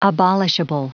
Prononciation du mot abolishable en anglais (fichier audio)
Prononciation du mot : abolishable